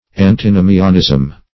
Antinomianism \An`ti*no"mi*an*ism\, n.